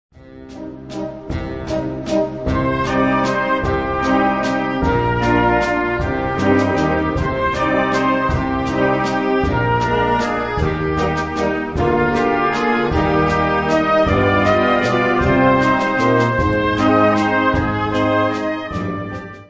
Kategorie Blasorchester/HaFaBra
Unterkategorie Konzertmusik
Besetzung Flexi (variable Besetzung)
Besetzungsart/Infos 5part; Perc (Schlaginstrument)